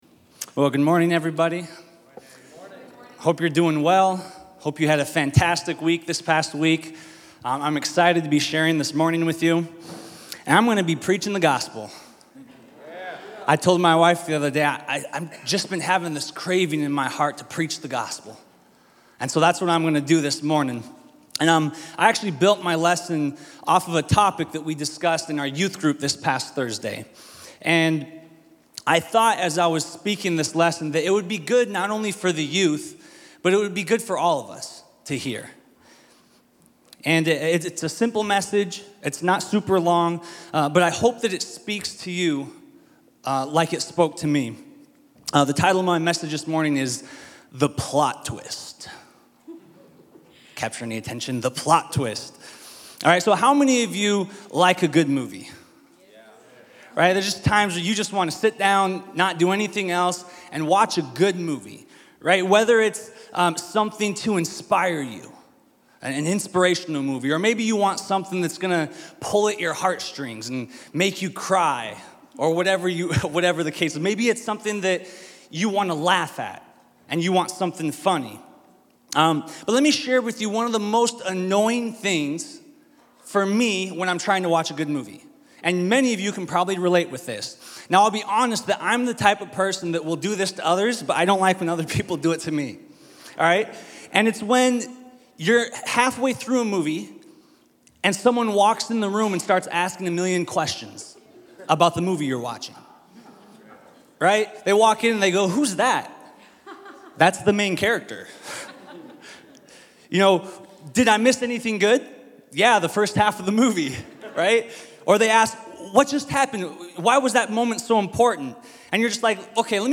Our messages are recorded at Times Square Church in New York City.